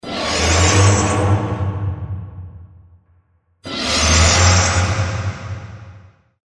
pendulum_scrape.mp3